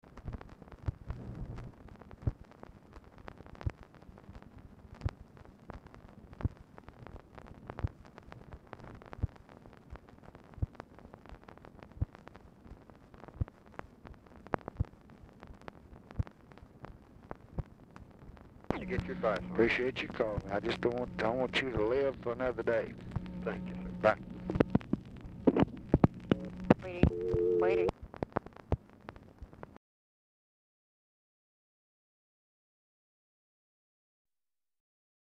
Telephone conversation # 10255, sound recording, LBJ and BIRCH BAYH, 6/23/1966, 6:45PM
Oval Office or unknown location
Telephone conversation
Dictation belt